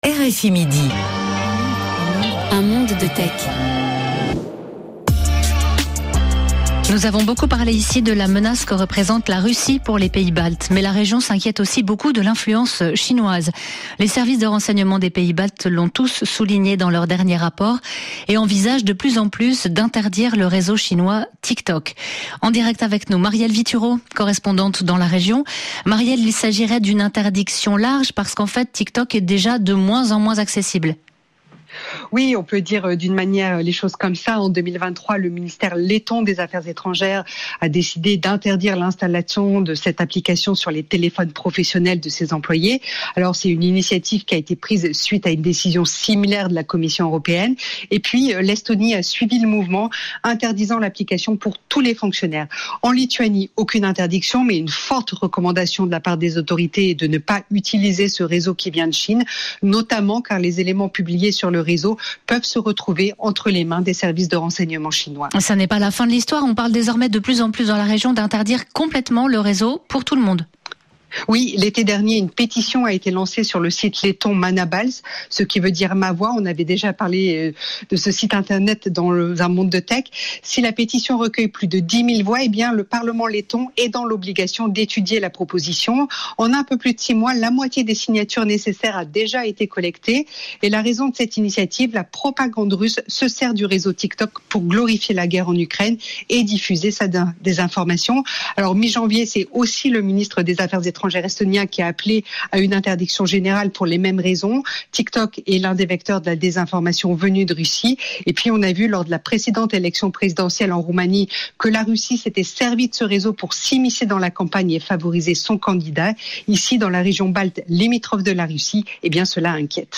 Depuis cinq ans, des associations proposent de se former aux premiers secours dans ce domaine dont PSSM. Reportage à Paris.…